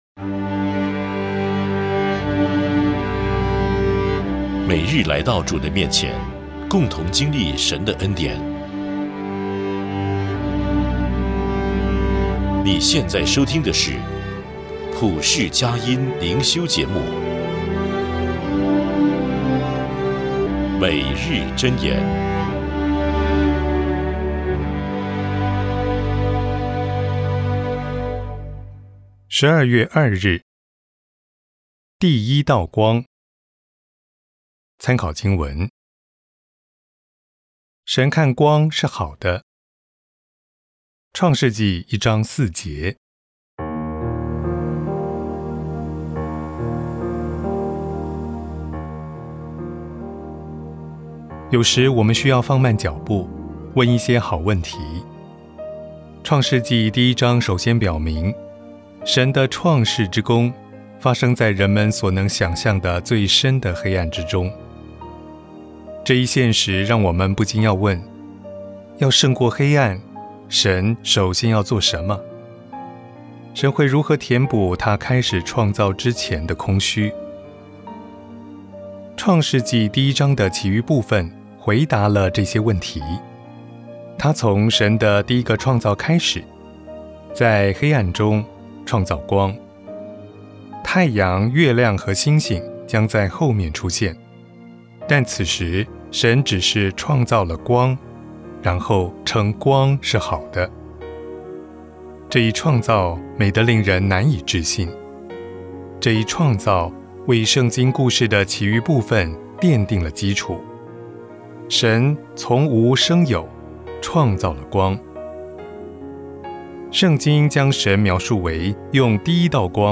【双语灵修】第一道光FIRST LIGHT